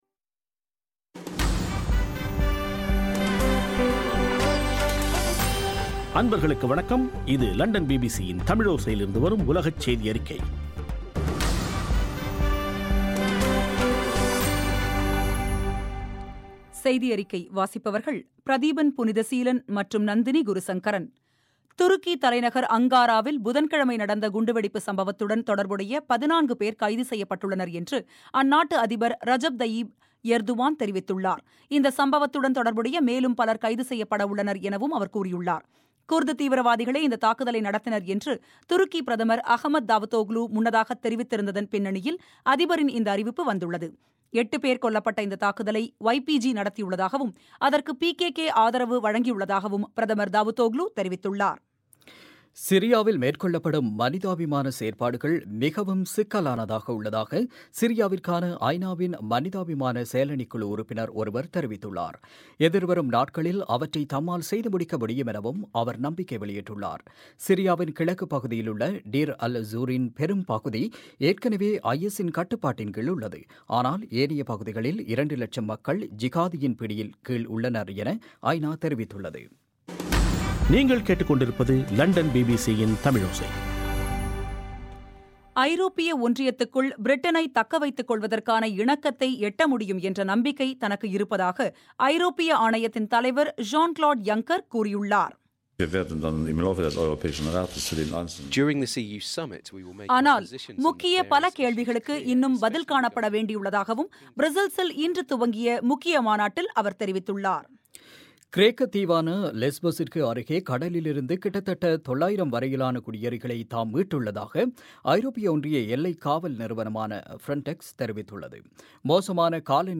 இன்றைய ( பிப்ரவரி 18)பிபிசி தமிழோசை செய்தியறிக்கை